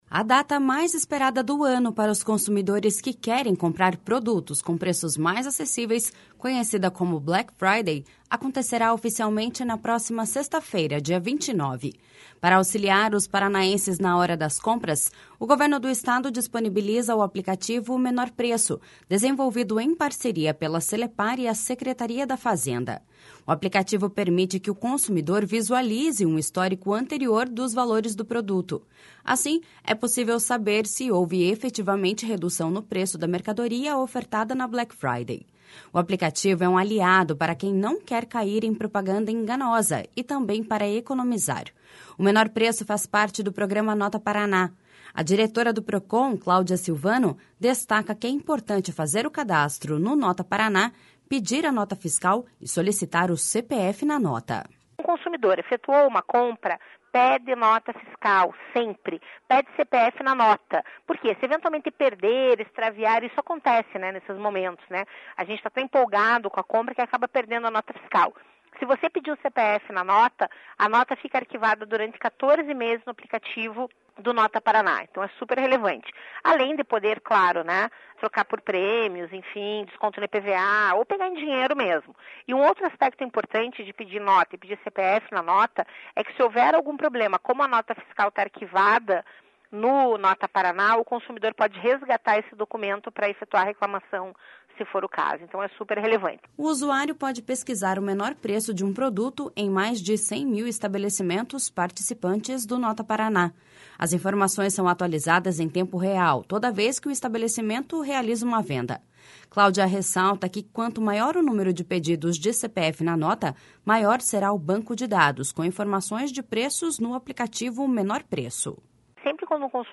A diretora do Procon, Claudia Silvano, destaca que é importante fazer o cadastro no Nota Paraná, pedir a nota fiscal e solicitar o CPF na nota.// SONORA CLAUDIA SILVANO.//